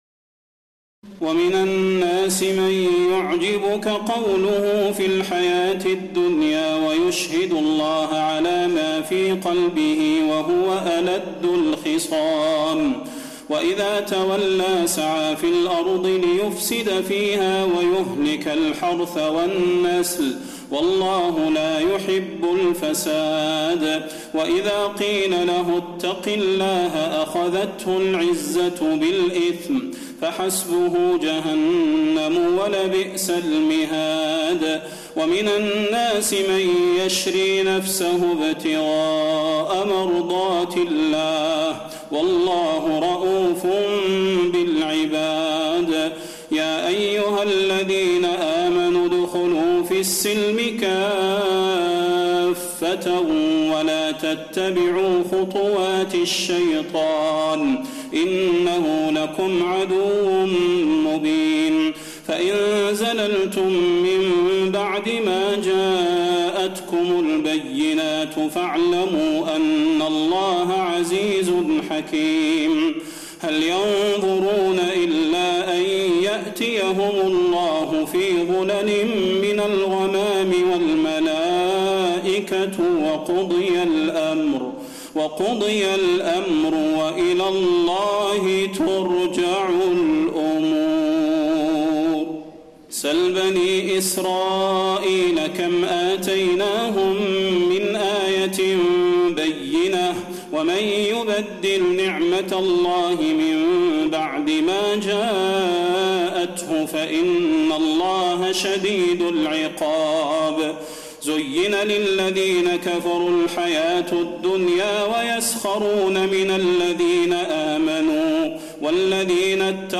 تراويح الليلة الثانية رمضان 1432هـ من سورة البقرة (204-252) Taraweeh 2 st night Ramadan 1432H from Surah Al-Baqara > تراويح الحرم النبوي عام 1432 🕌 > التراويح - تلاوات الحرمين